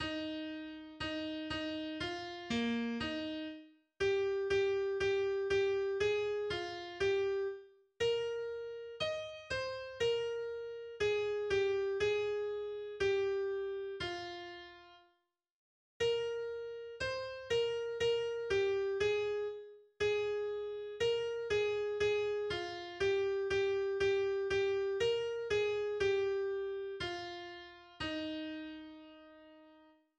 Weihnachtslied auf eine Melodie aus dem 12. Jahrhundert.